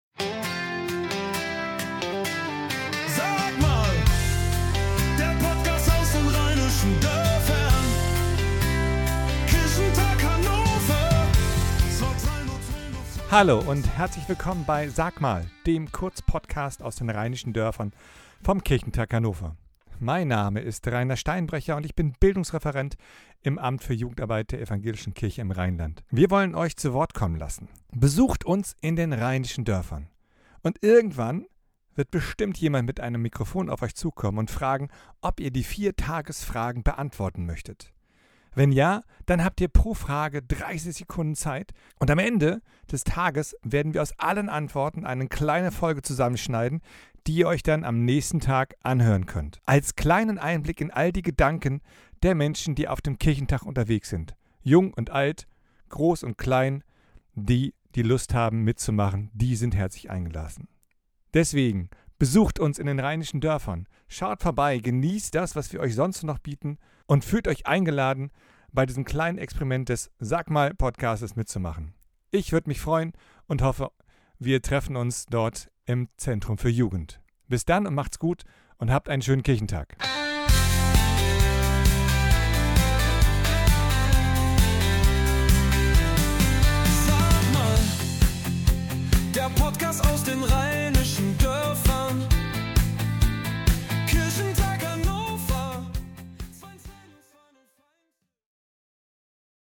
Sag mal, der Podcast aus den Rheinischen Dörfern vom Kirchentag